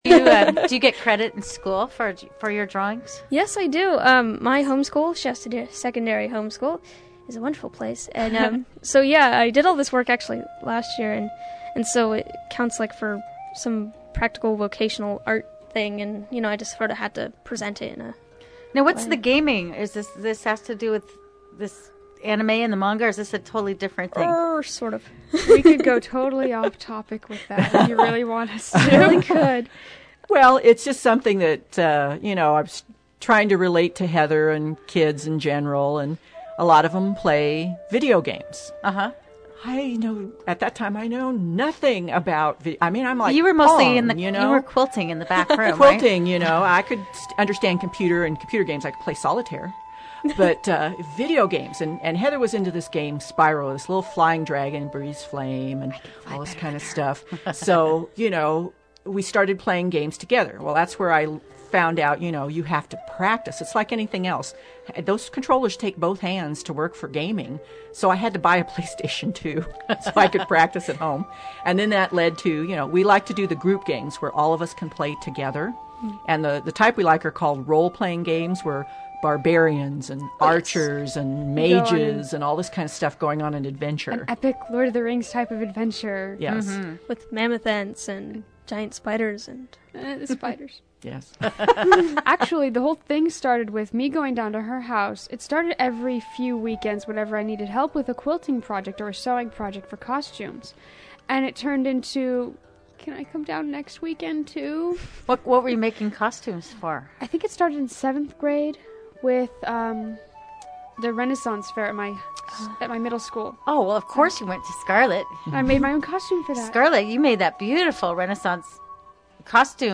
The I-5 Live show was a one hour live program that covered anime and quilting, with everyone there in the studio.
Here are MP3 files of the I-5 Live interview, broken into 5 parts because of their size.